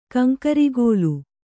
♪ kaŋkarigōlu